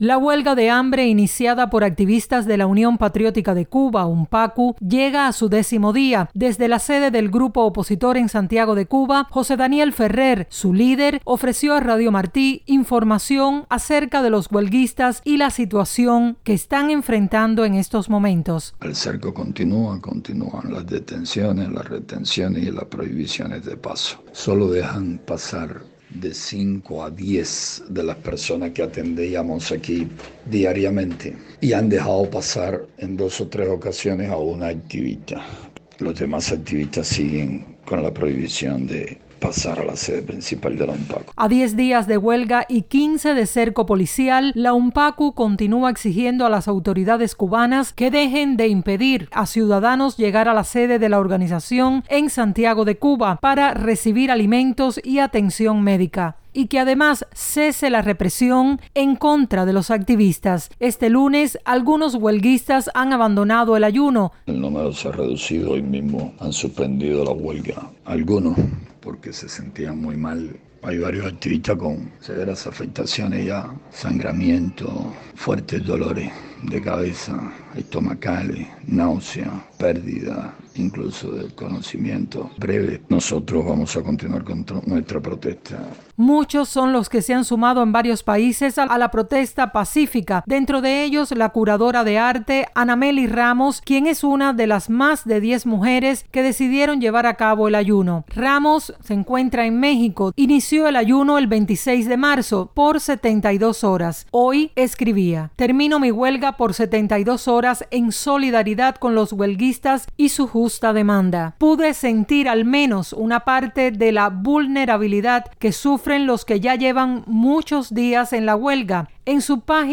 “El cerco continúa. Continúan las detenciones, retenciones y las prohibiciones de paso. Sólo dejan pasar de cinco a diez de las personas que atendíamos aquí diariamente, y han dejado pasar, en dos o tres ocasiones, a una activista. Los demás siguen con la prohibición de pasar a la sede principal de la UNPACU”, dijo el líder del grupo, José Daniel Ferrer, a Radio Martí.